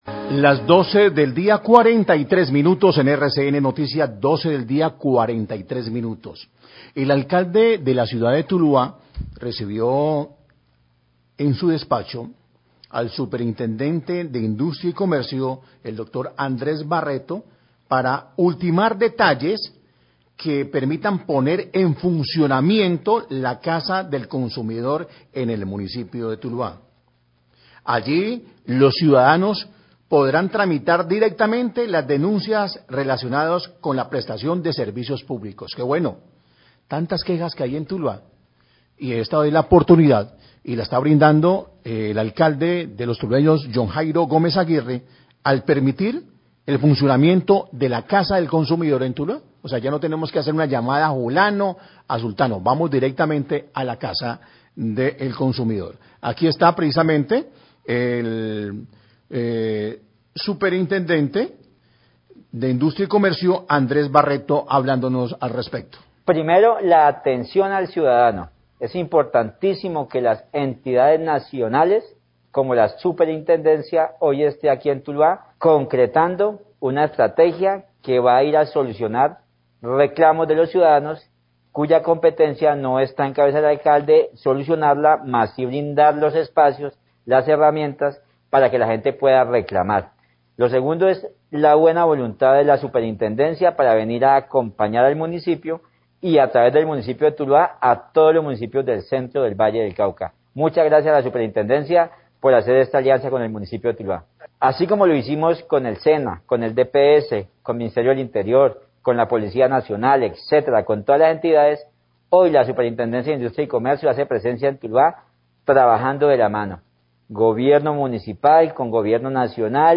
Radio
El alcalde de Tuluá y el Superintendente de Industria y Comercio, Andrés Barreto, hablan sobre la visita del bus oficina de la institución para recepcionar las PQR y la posibilidad de la instalación de una Casa del Consumidor en la ciudad.